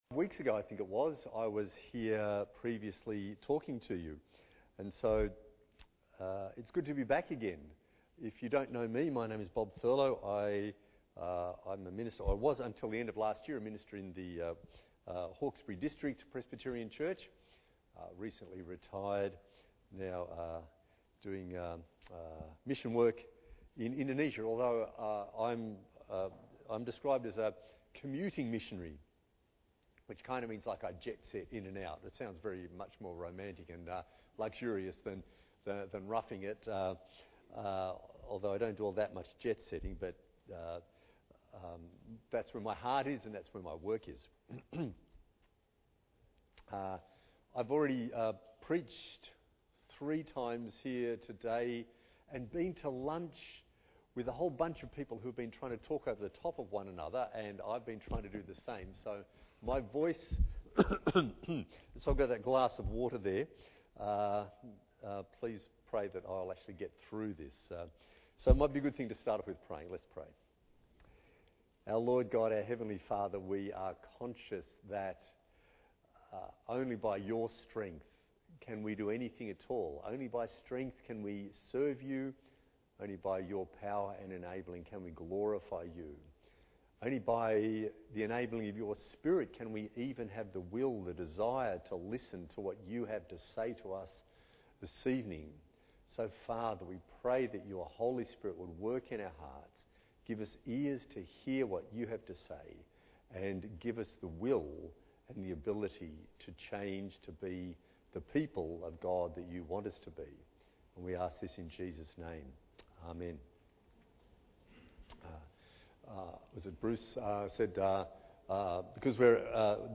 Guest Preachers Passage